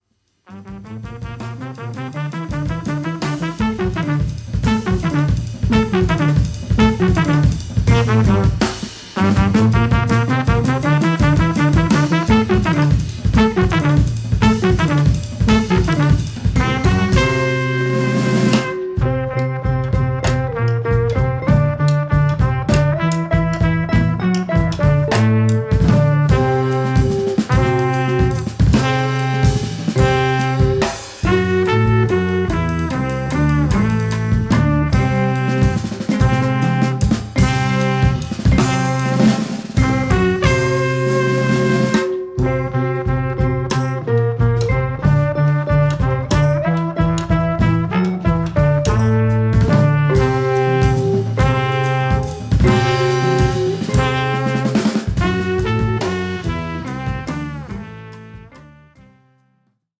コルネット奏者